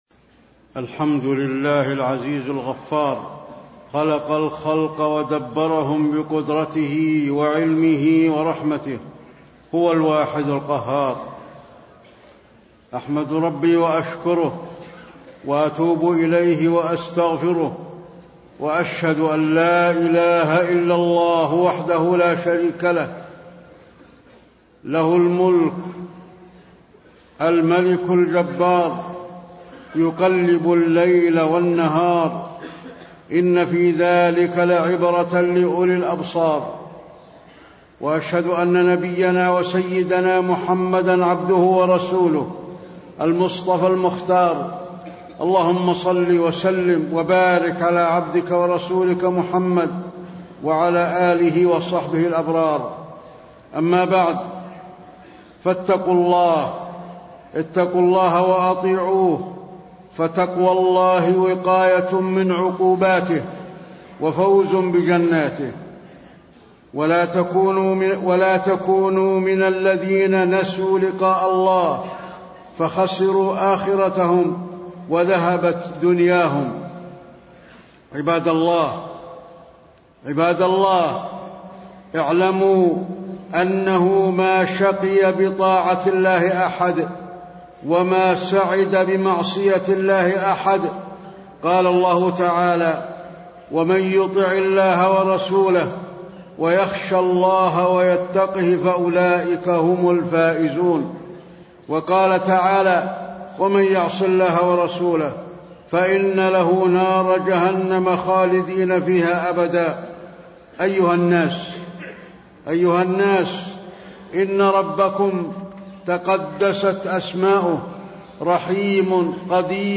تاريخ النشر ٢ محرم ١٤٣٤ هـ المكان: المسجد النبوي الشيخ: فضيلة الشيخ د. علي بن عبدالرحمن الحذيفي فضيلة الشيخ د. علي بن عبدالرحمن الحذيفي سعادة العبد في طاعة ربه